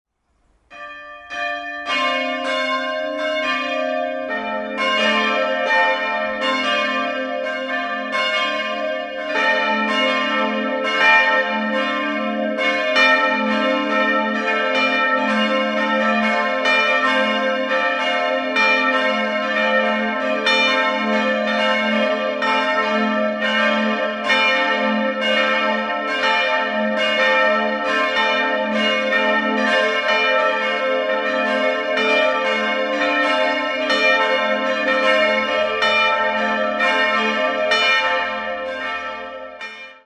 Der helle Kirchenraum hat einen achteckigen Grundriss. 3-stimmiges TeDeum-Geläute: a'-c''-d'' Die Glocken stammen aus der Gießerei Karl Czudnochowsky in Erding und wurden 1955 in zinnfreier Legierung gegossen.